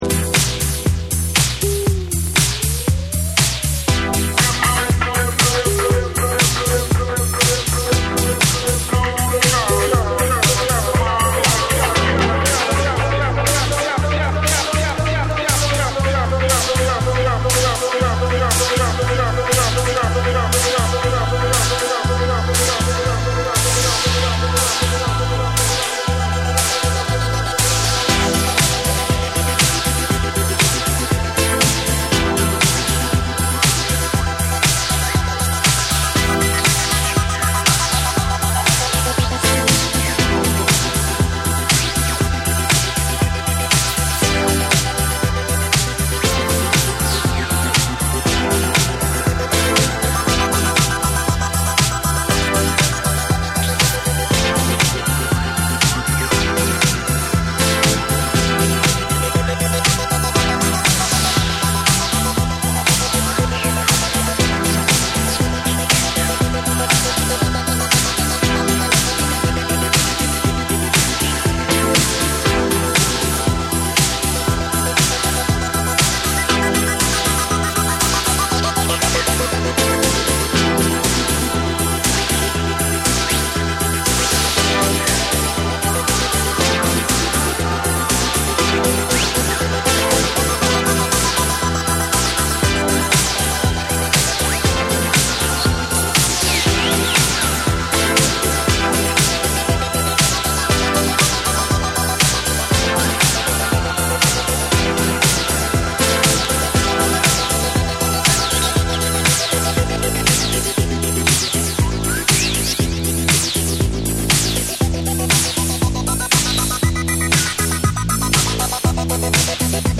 DANCE CLASSICS / DISCO